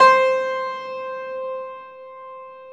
53c-pno14-C3.wav